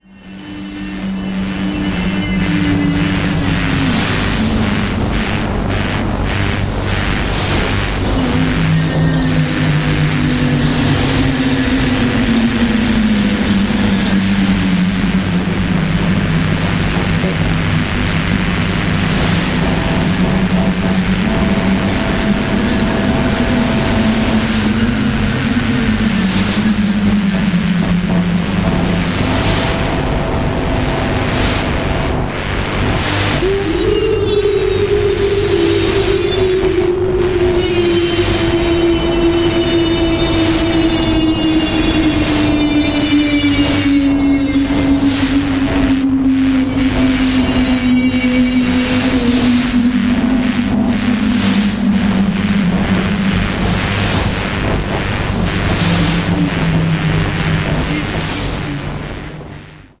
quartet from Latvia
Very experimental avantgarde atmospheric art noise.